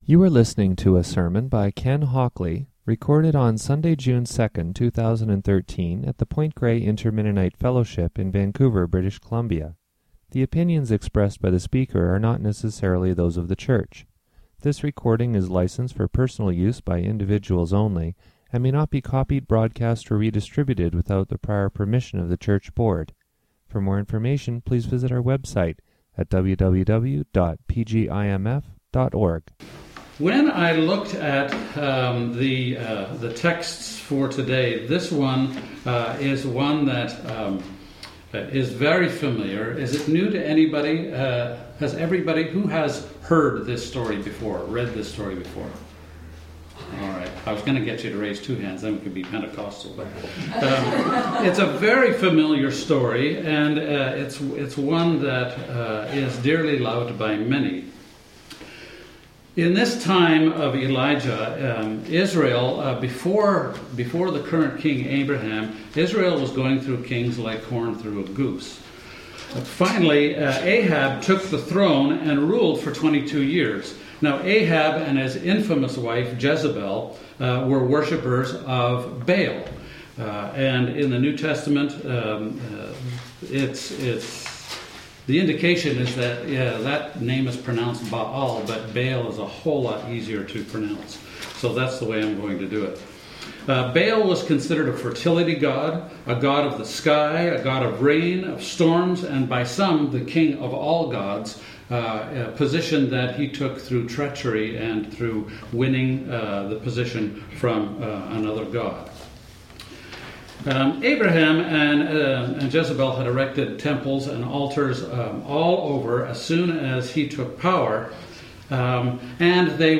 Labels: PGIMF sermon discussion